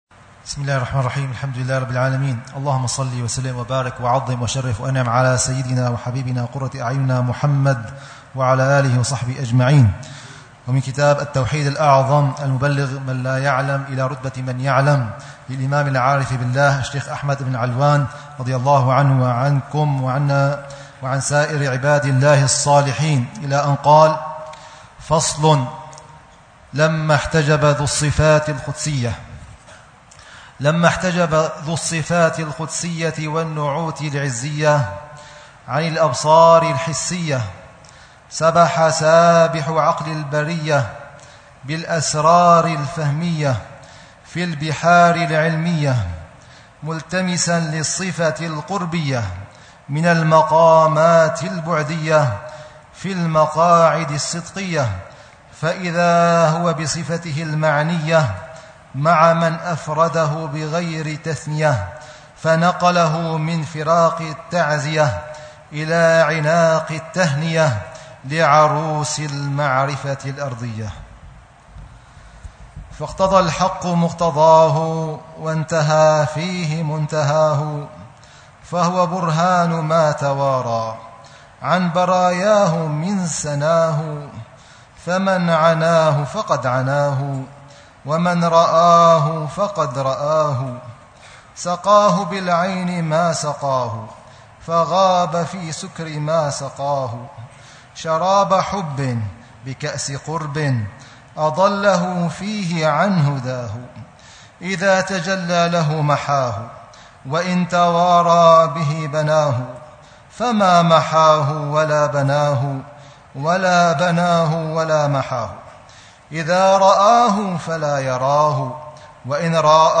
شرح الحبيب عمر بن حفيظ على كتاب: التوحيد الأعظم المبلغ من لا يعلم إلى رتبة من يعلم، للإمام الشيخ أحمد بن علوان، ضمن دروس الدورة الرابع